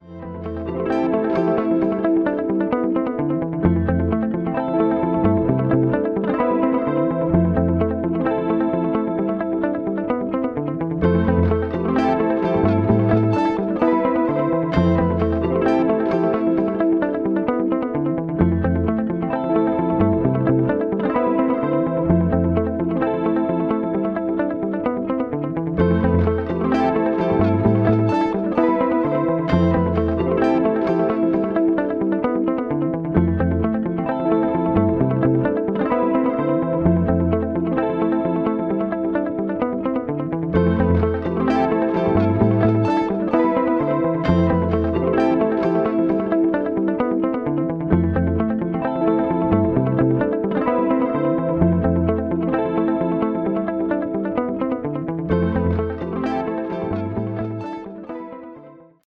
Here, again, I recorded a loop that had impact and charm, saved it, then did nothing with it.